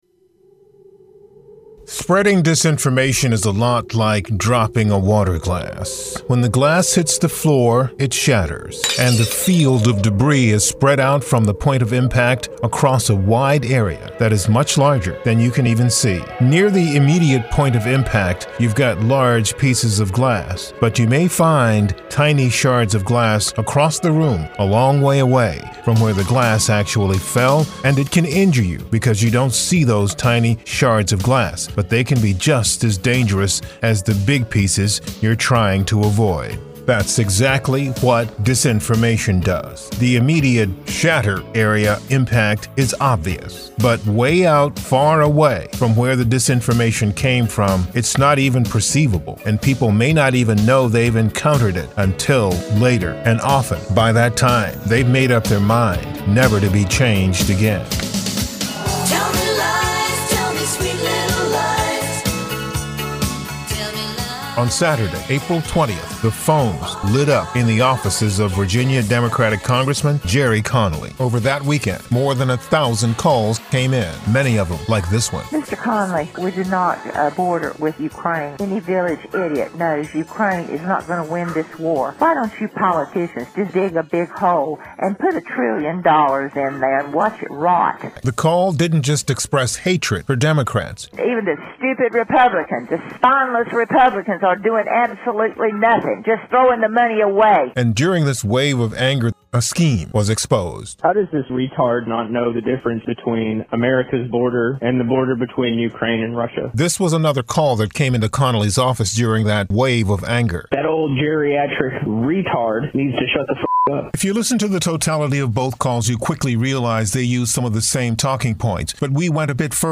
speaks with multiple experts